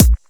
Kick OS 15.wav